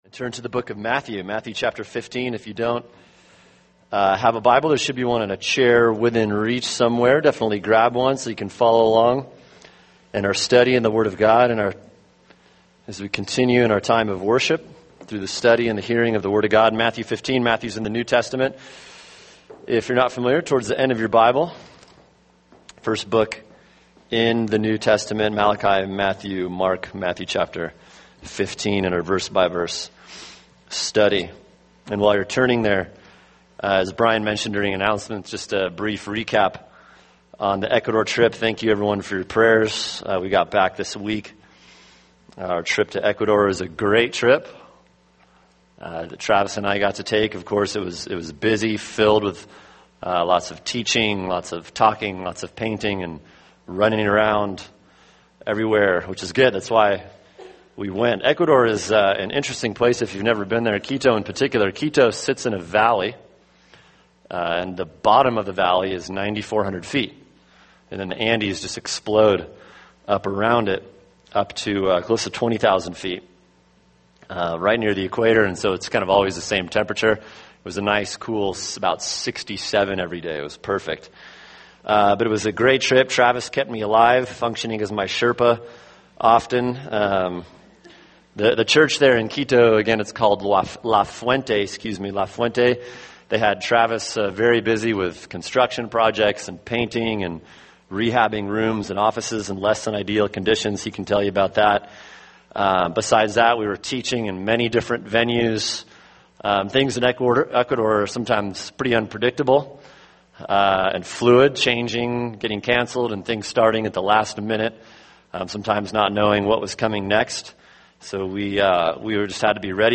[sermon] Matthew 15:10-20 – Moral-Spiritual Pollution and Purity | Cornerstone Church - Jackson Hole